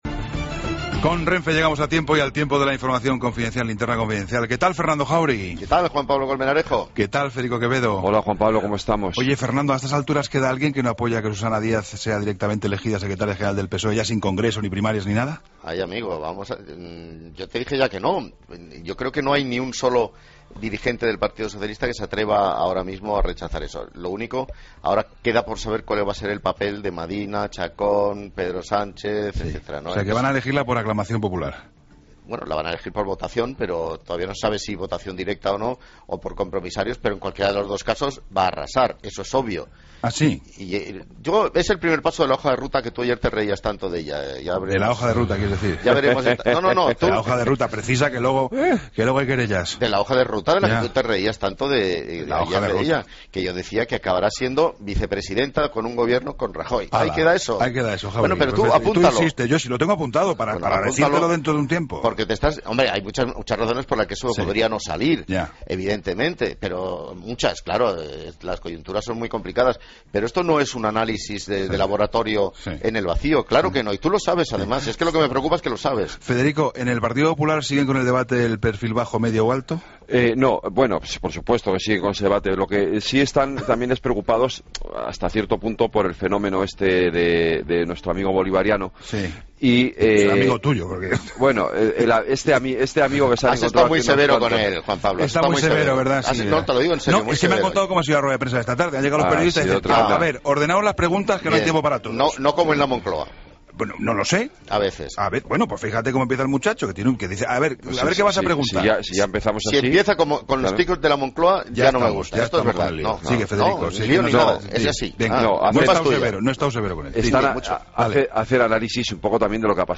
Incluye entrevista a María Dolores de Cospedal, presidenta de Castilla La Mancha y secretaria...